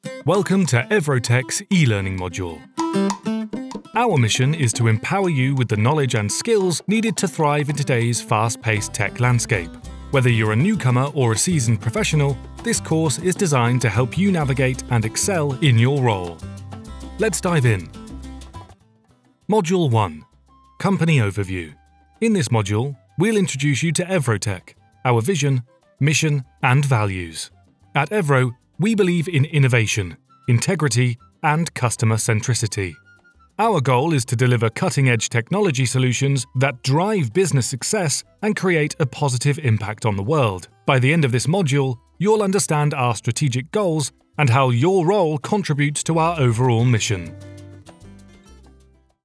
An engaging and warm British voice.
E - Learning Module
Middle Aged